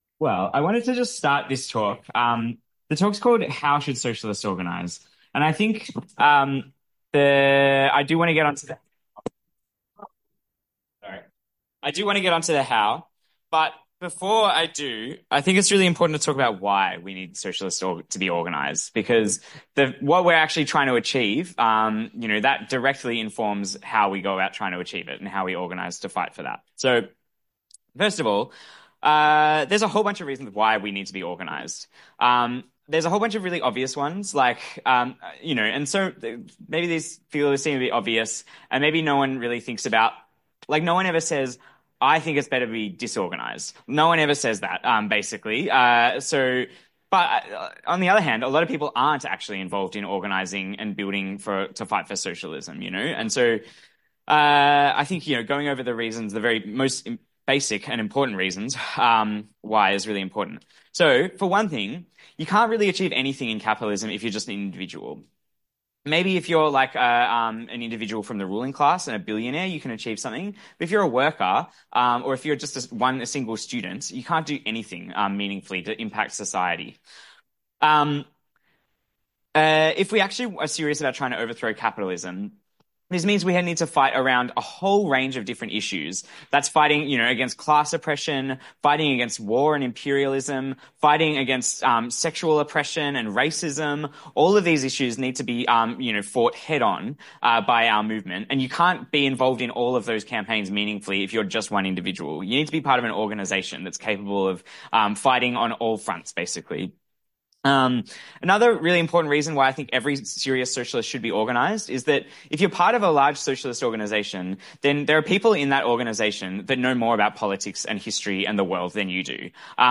Socialism 2025 (Brisbane)